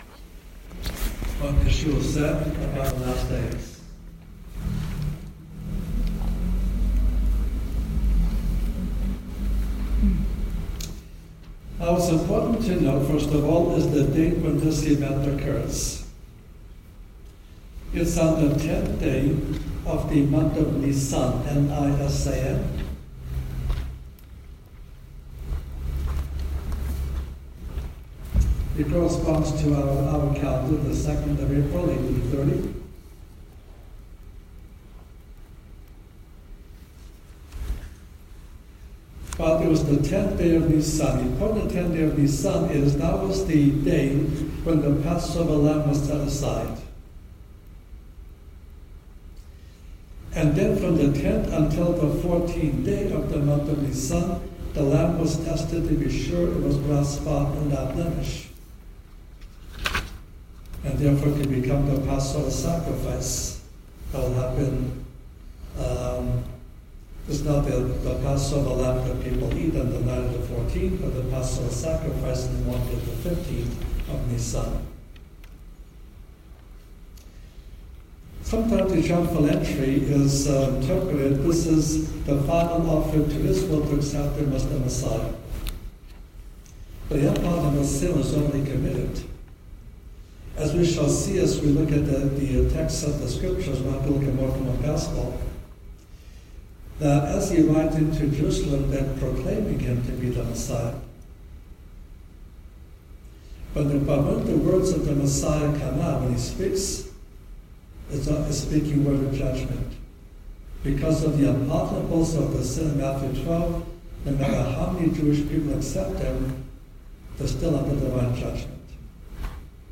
God, Lord God Most High, Bible, Christian, Christianity, Jesus Christ, Jesus, salvation, good news, gospel, messages, sermons